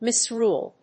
音節mis･rule発音記号・読み方mɪ̀srúːl
• / mɪˈsrul(米国英語)
• / mɪˈsru:l(英国英語)